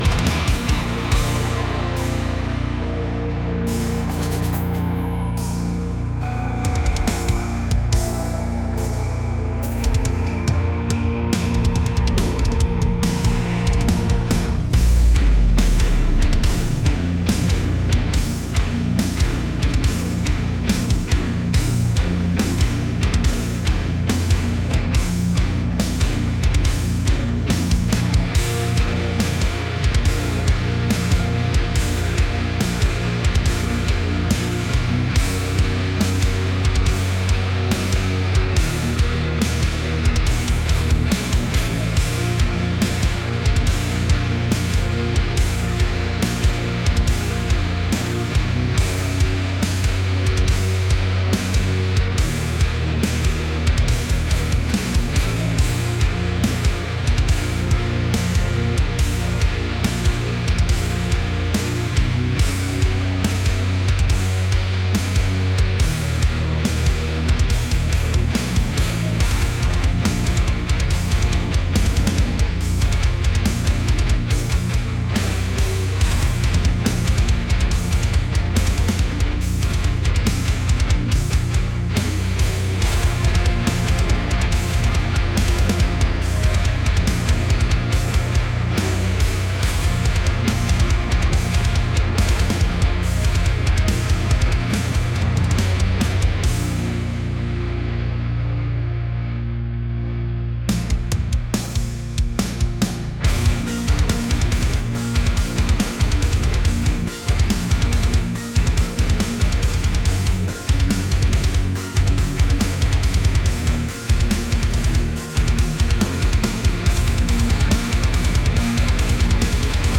metal | fusion